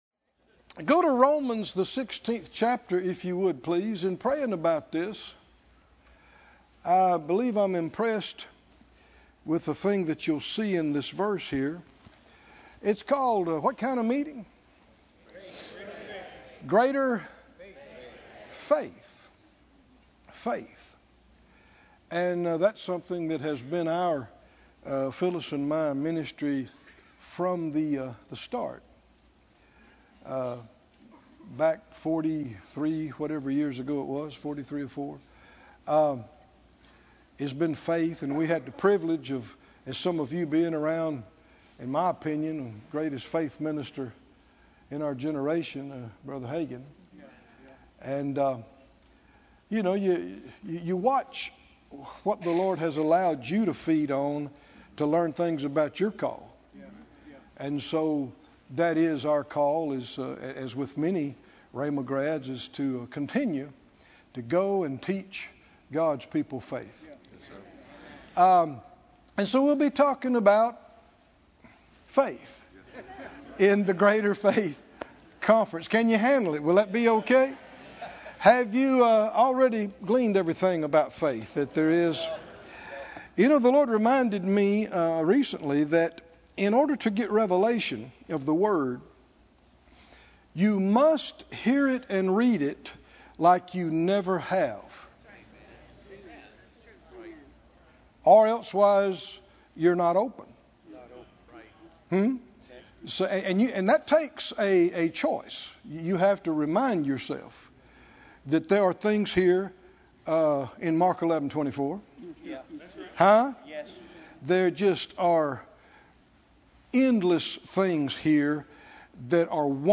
The Greater Faith Conference 2025: The Obedience Of Faith - Pt. 1 - The Faith Of Abraham